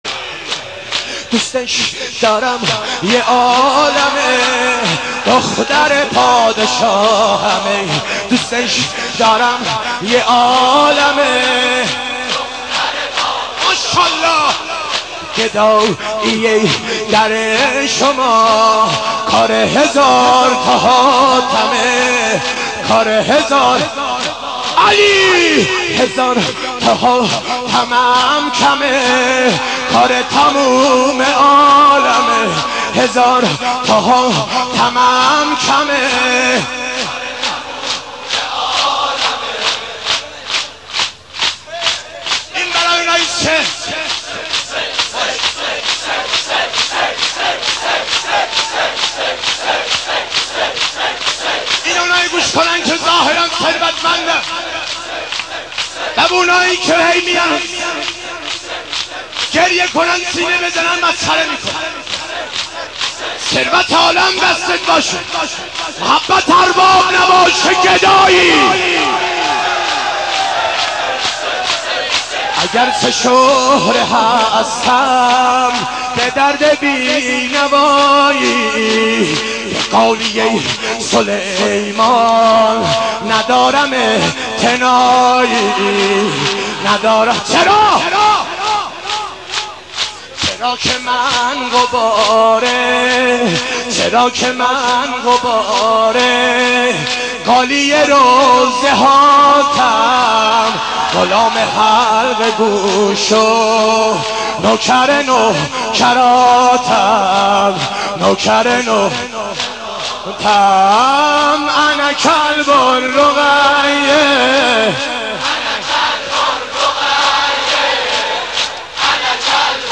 مداحی های ویژه عروج مرحوم سیدجواد ذاکر
عقیق:مداحی های زیبای مرحوم سیدجواد ذاکر